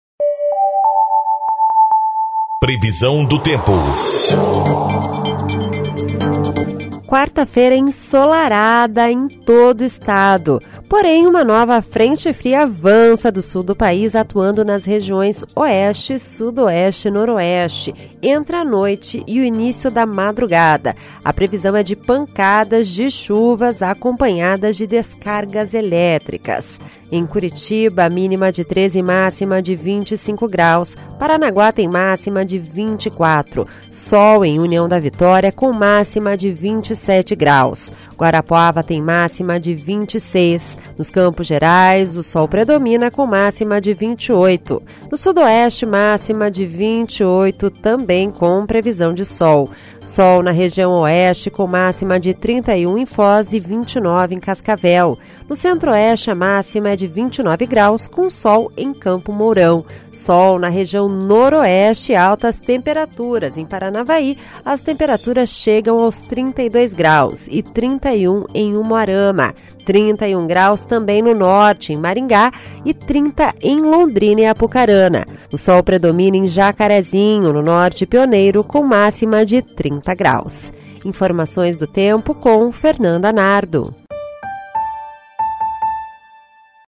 Previsão do tempo (26/10)